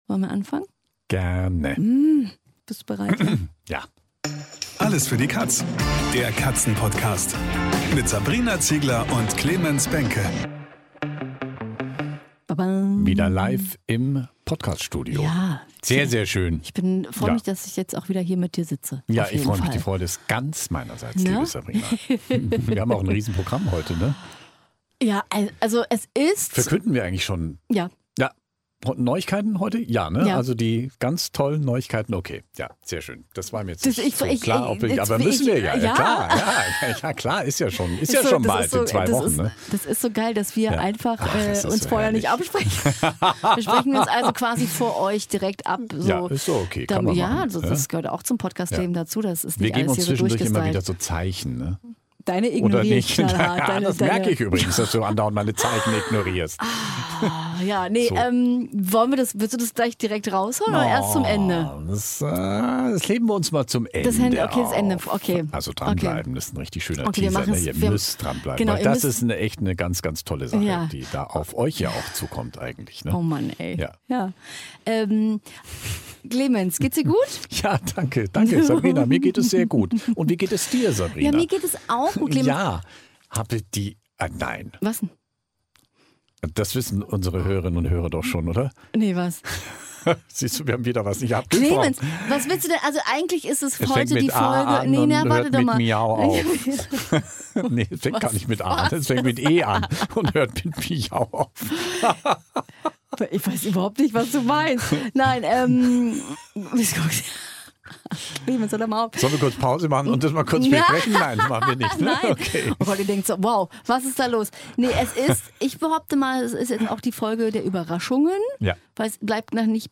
wenn ihr reinhört!Außerdem im Interview